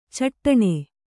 ♪ caṭṭaṇe